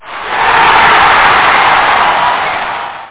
Amiga 8-bit Sampled Voice
crowd.mp3